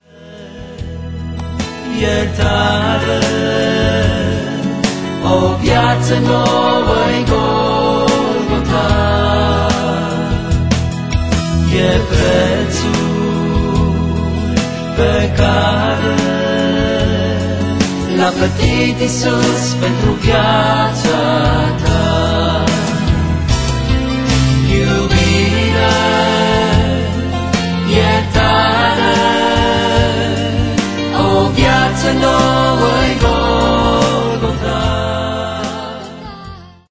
aranjamentul instrumental